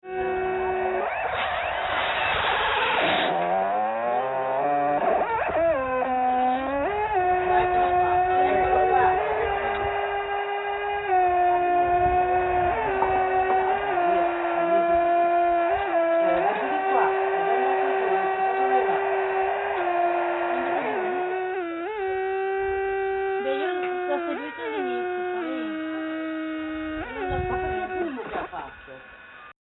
Le registrazioni originali su cassette sono tuttora conservate nel mio archivio, e le digitalizzazioni degli anni 1993-1995 sono state effettuate con software buoni sebbene non eccelsi, e a risoluzioni "decenti" a 44Khz.